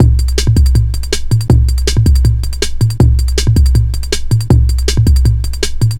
Index of /90_sSampleCDs/Zero-G - Total Drum Bass/Drumloops - 1/track 14 (160bpm)